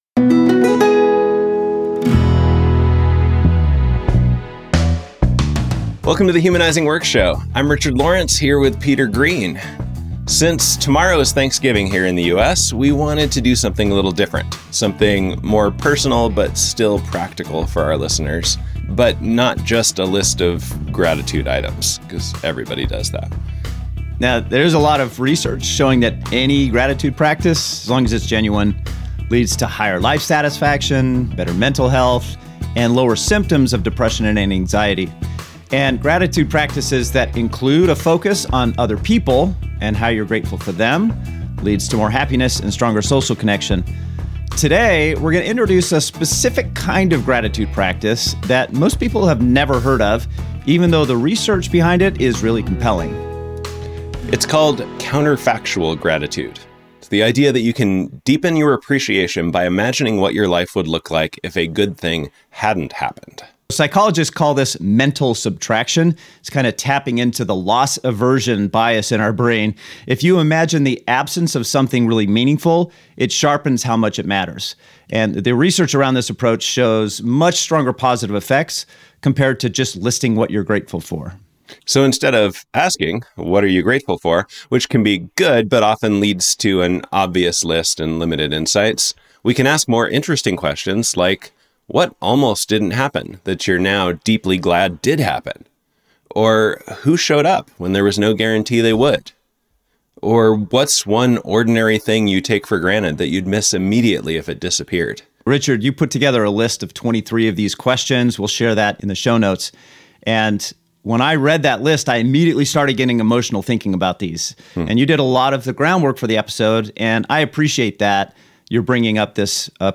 In this conversation, we explore a deeper, more effective approach.